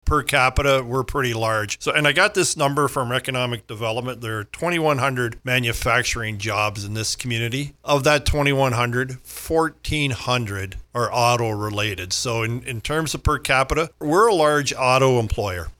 Strathroy-Caradoc Mayor Colin Grantham says we need to protect our local jobs.